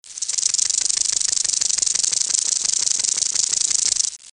Western Diamondback Rattlesnake